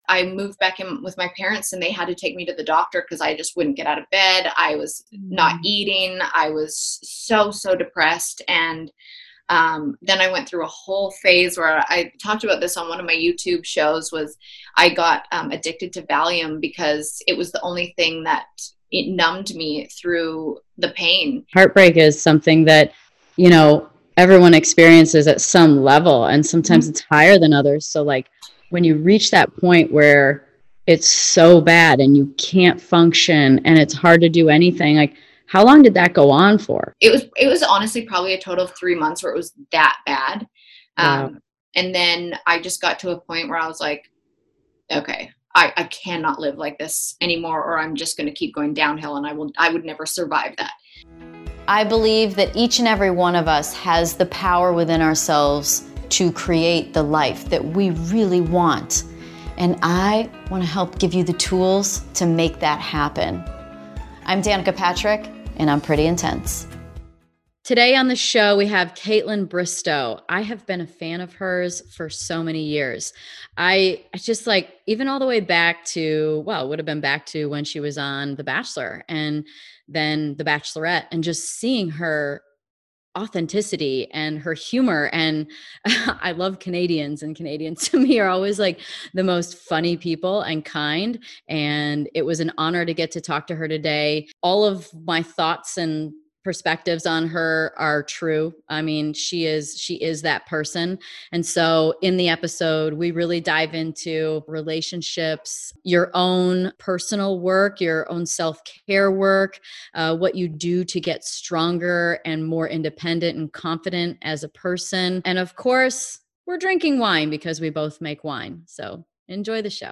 We talk wine, and even drink a little wine on the show today.